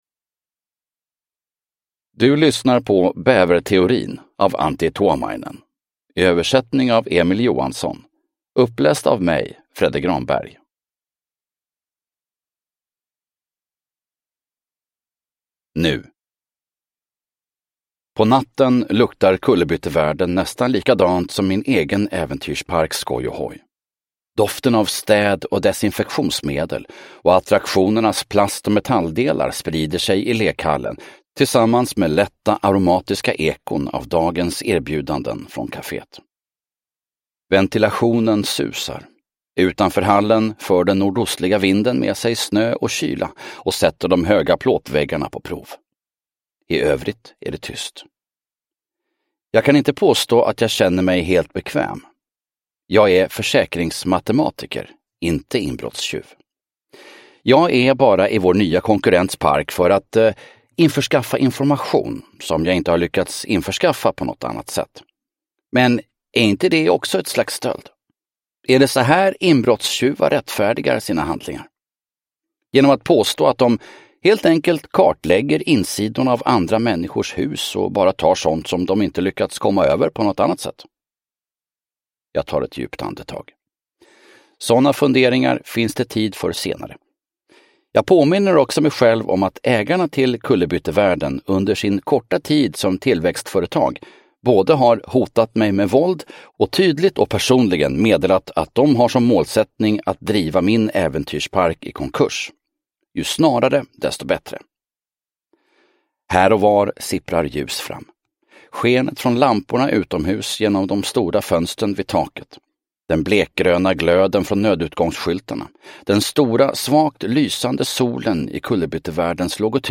Bäverteorin (ljudbok) av Antti Tuomainen